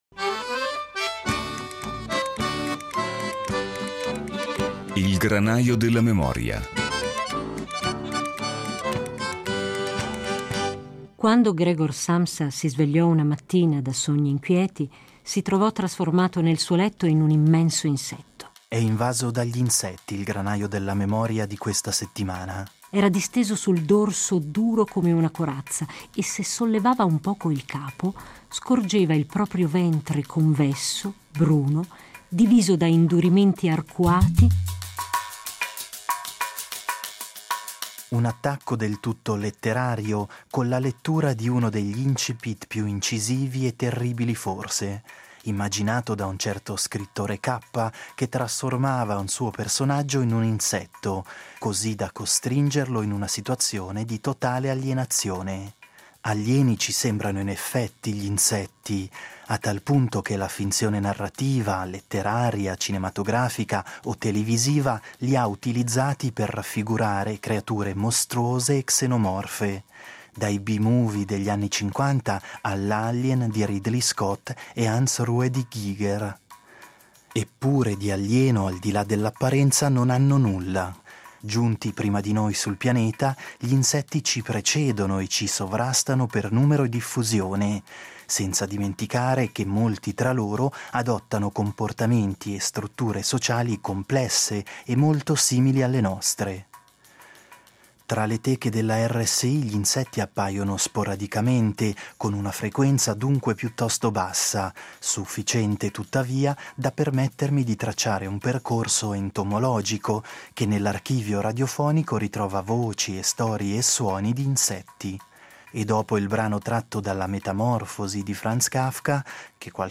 Una rappresentazione certamente ingiustificata, visto che gli insetti sono organismi complessi, affascinanti e indispensabili alla vita degli ecosistemi e alla biodiversità. Tra le teche della RSI api, formiche, mosche e altri insetti appaiono sporadicamente in alcuni dei documenti sonori conservati dall’archivio radiofonico: “Il Granaio della memoria” li ha utilizzati per comporre una piccola raccolta di memorie entomologiche.